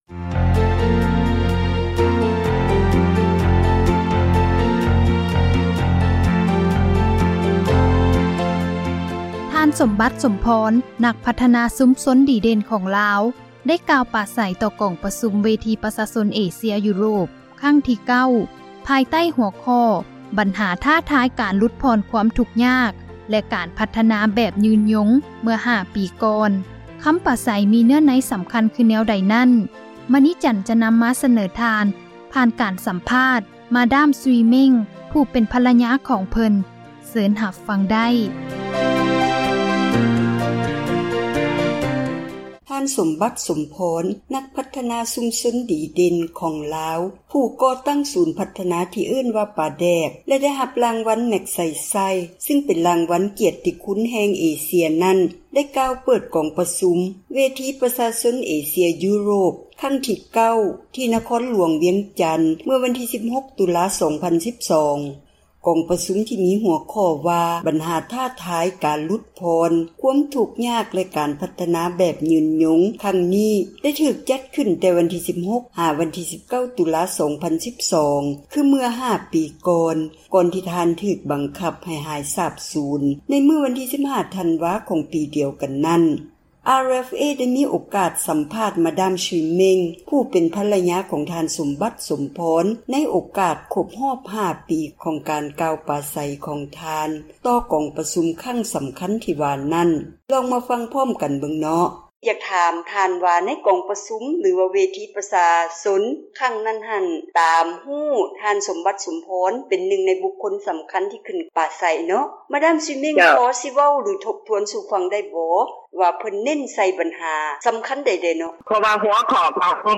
ທ່ານ ສົມບັດ ສົມພອນ ນັກພັທນາຊຸມຊົນ ດີເດັ່ນ ຂອງລາວ ເຄີຍໄດ້ກ່າວ ໄວ້ ໃນກອງປະຊຸມ ເວທີ ປະຊາຊົນ ເອເຊັຽ-ຢູໂຣບ ຄັ້ງທີ 9.